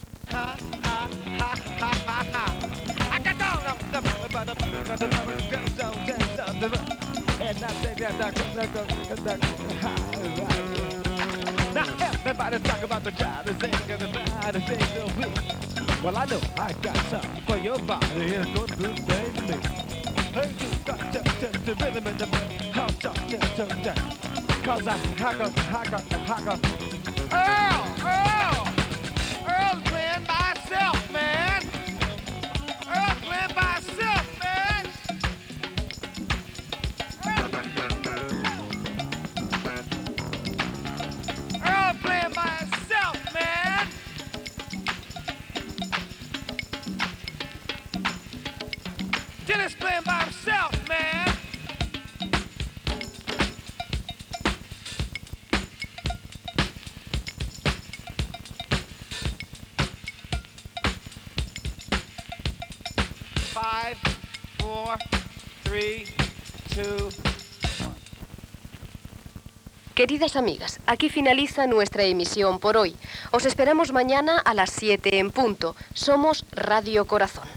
865b348a819c964f997249cf4e722e50b905aae1.mp3 Títol Radio Corazón Emissora Radio Corazón Titularitat Privada local Descripció Tema musical i tancament de l'emissió. Gènere radiofònic Musical